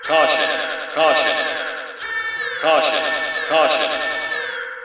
hullbreech.wav